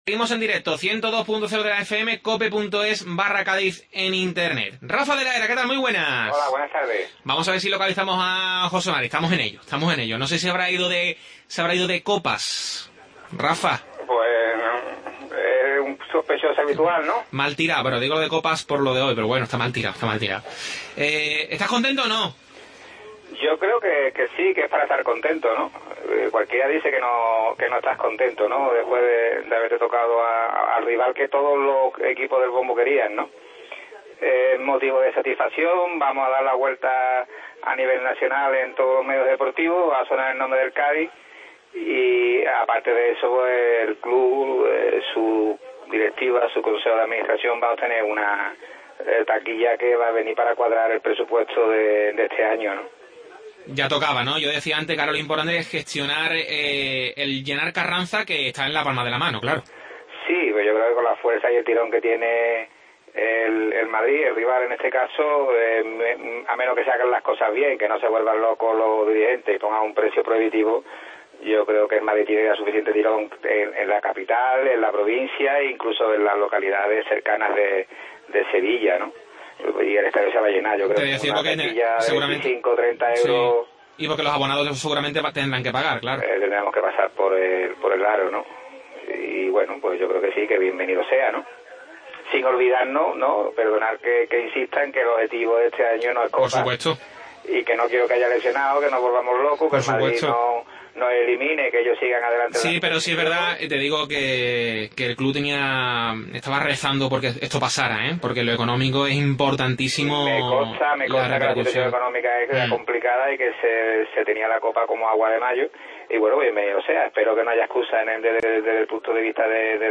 Debate